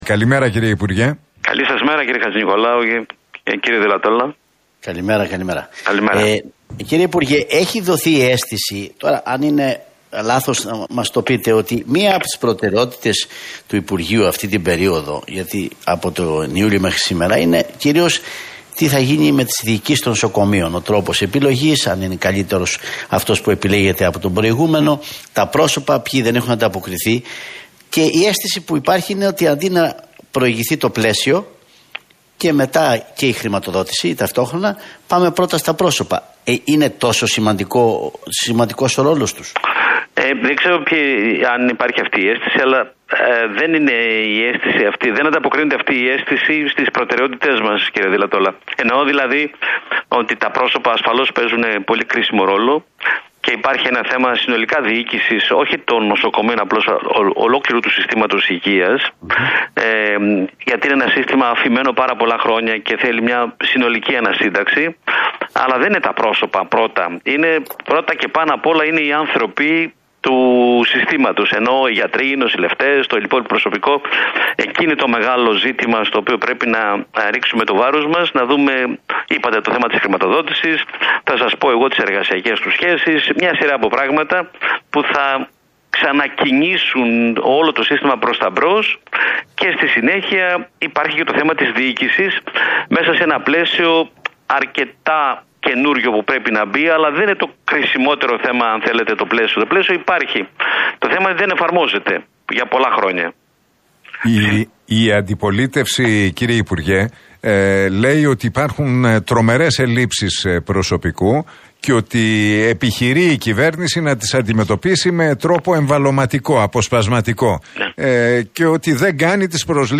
Χρυσοχοΐδης στον Realfm 97,8: Συνολική ανασύνταξη στο ΕΣΥ - Τι είπε για τις καθυστερήσεις στα χειρουργεία